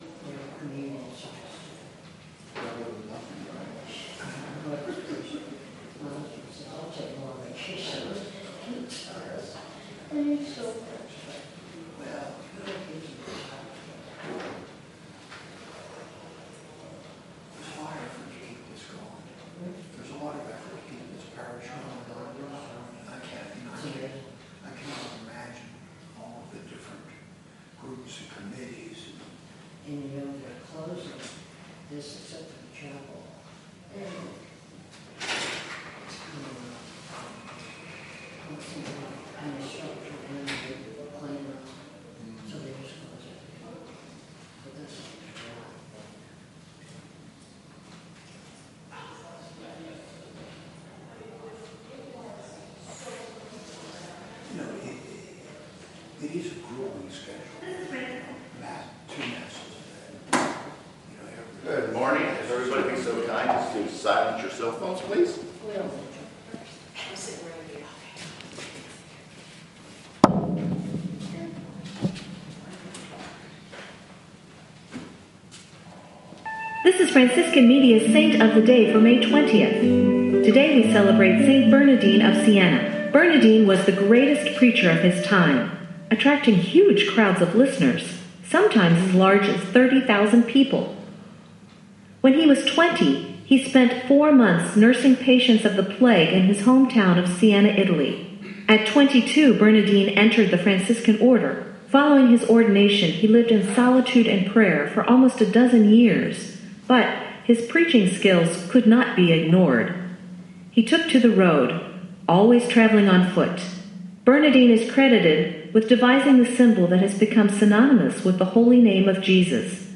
Livestream Mass